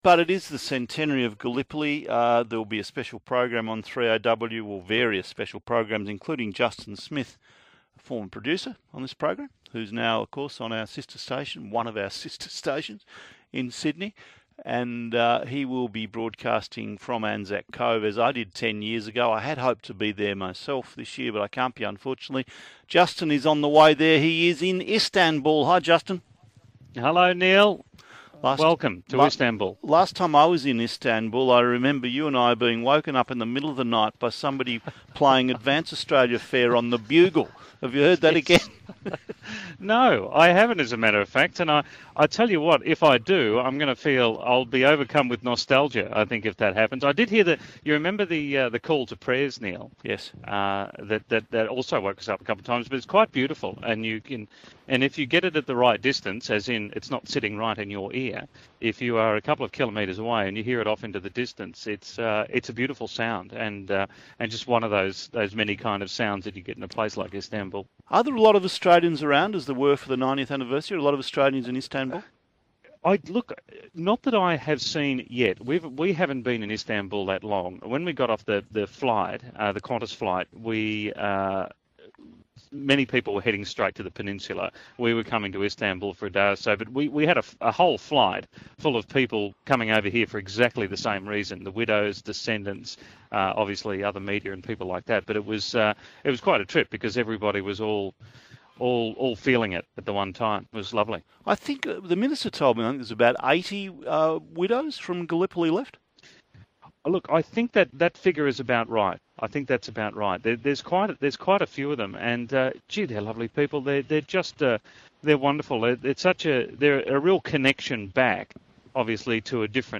live from Turkey with Neil Mitchell